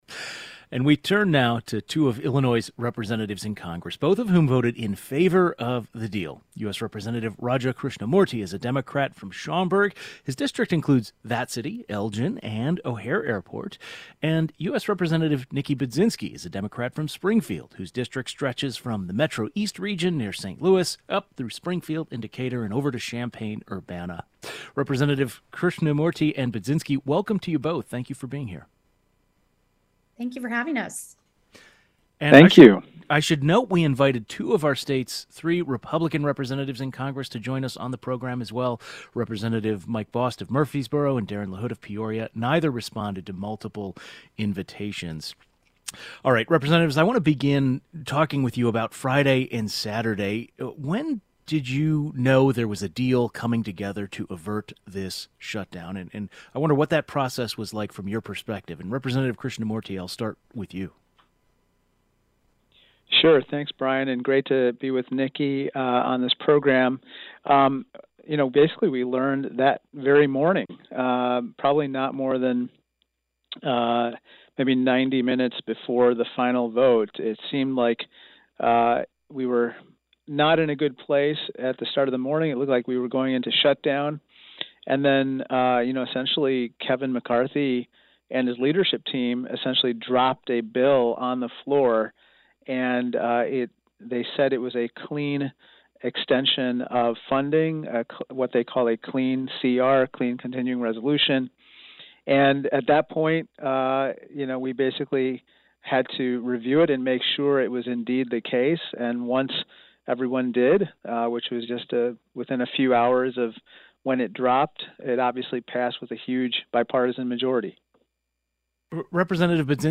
IL Reps. Krishnamoorthi and Budzinski talk about averting a government shut down